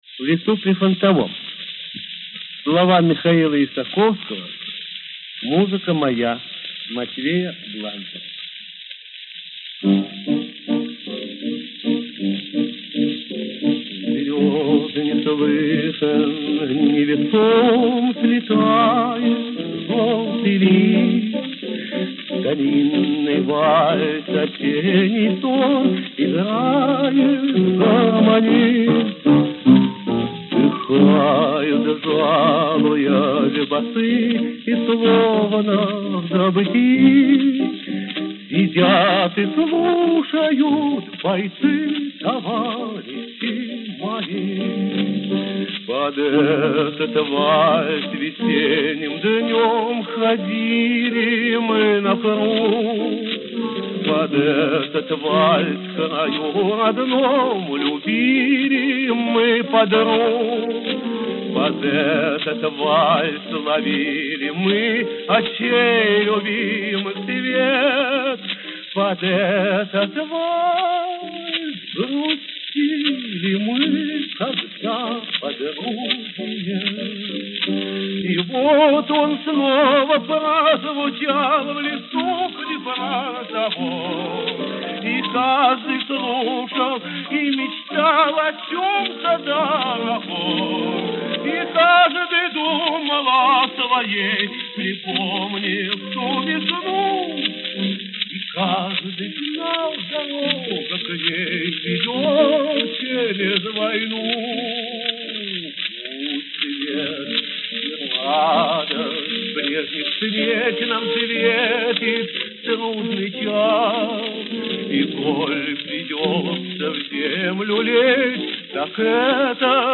Фрагмент Берлинского концерта 7 мая 1945 года.